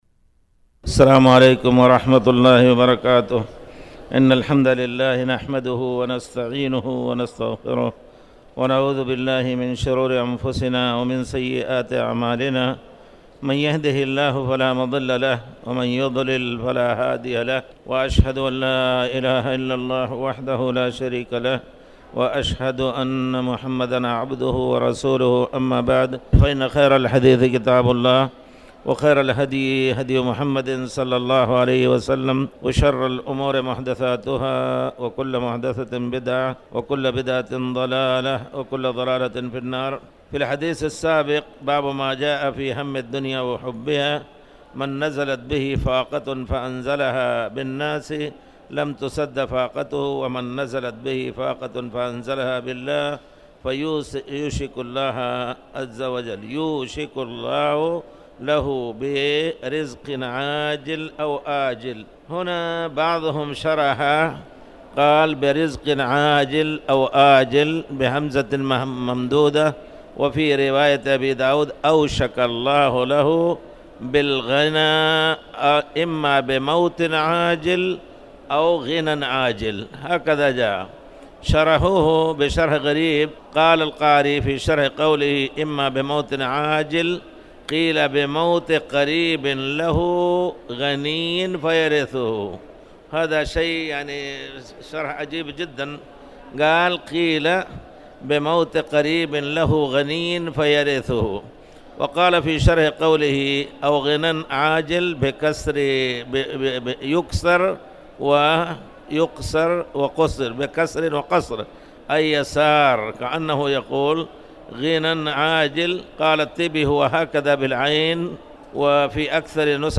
تاريخ النشر ٨ جمادى الأولى ١٤٣٩ هـ المكان: المسجد الحرام الشيخ